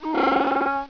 • Chewbacca growing -1.